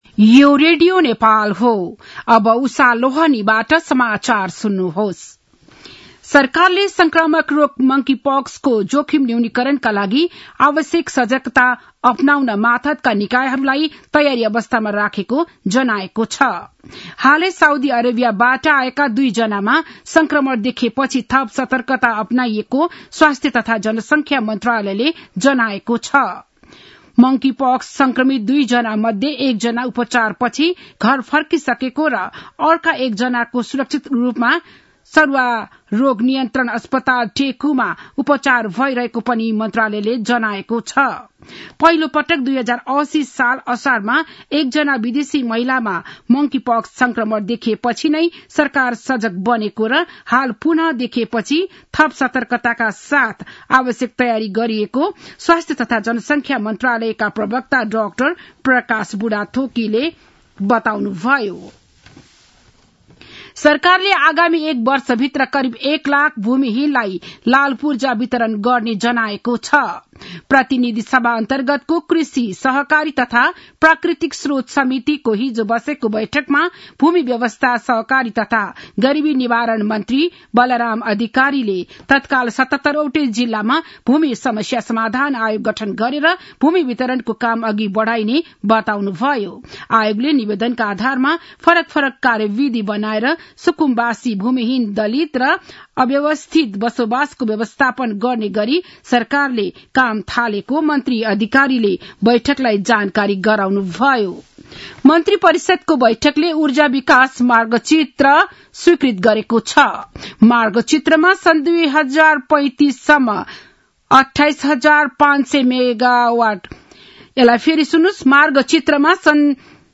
बिहान ११ बजेको नेपाली समाचार : १९ पुष , २०८१
11-am-nepali-news-1-1.mp3